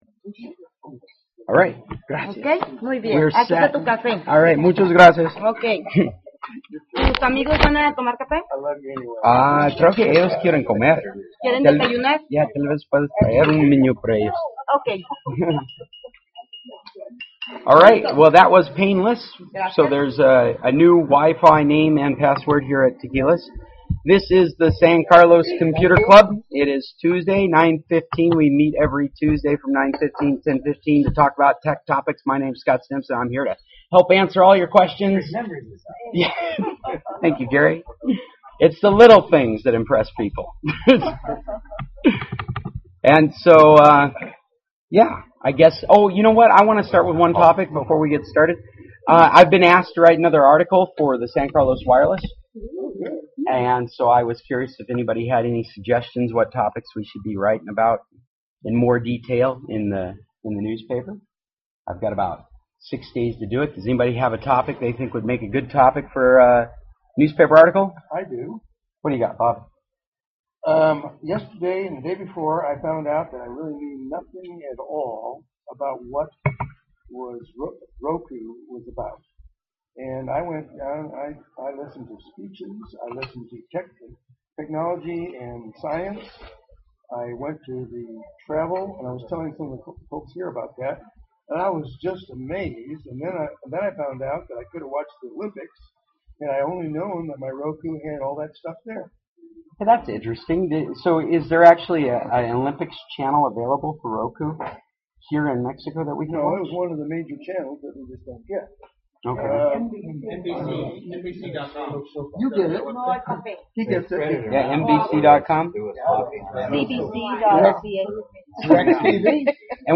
Feb. 25, 2014 Club Meeting